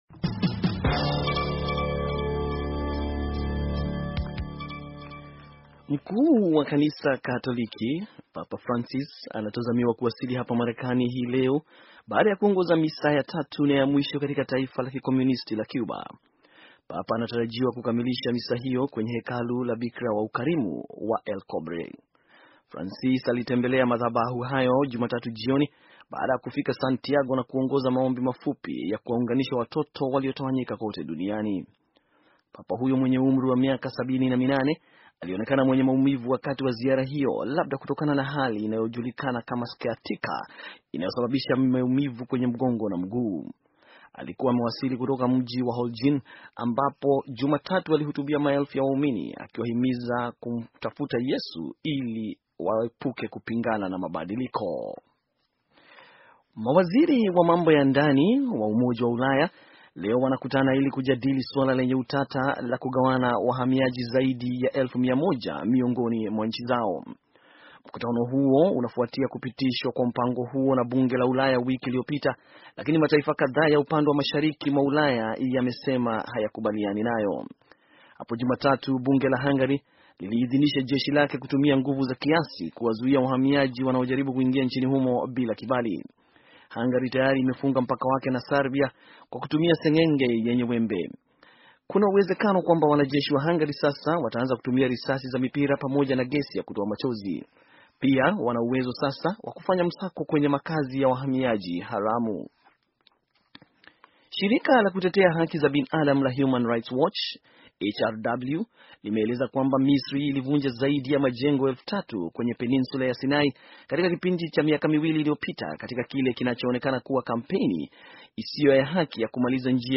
Taarifa ya habari - 5:46